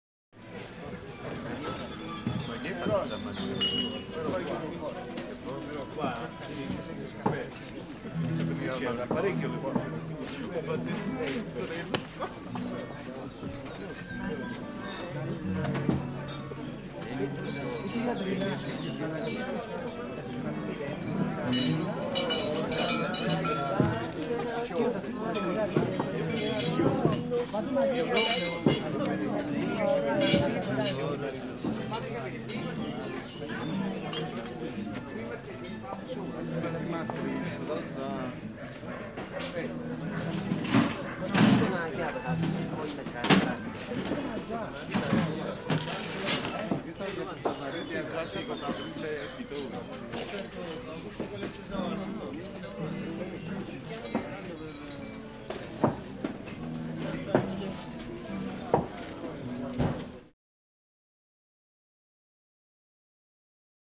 Restaurant - Italian Restaurant, Kitchen Walla